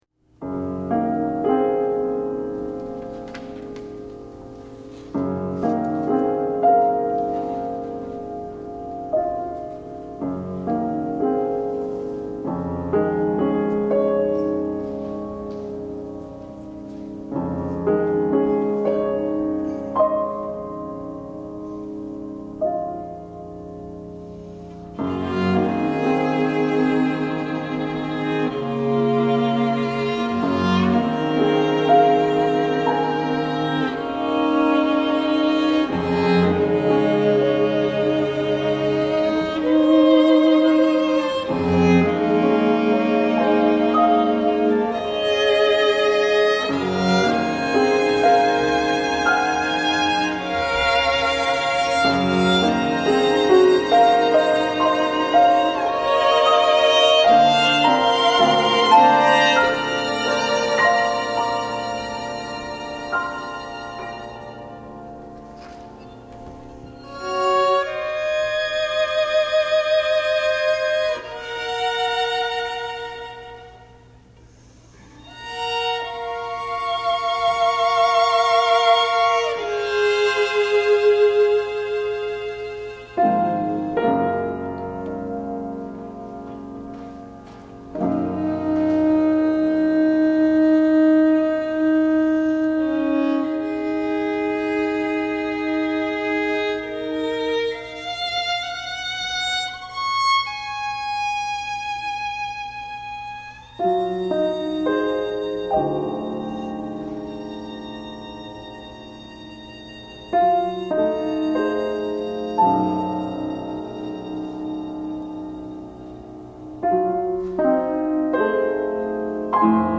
Grabación en vivo del estreno en Ithaca, NY, Marzo 27, 2011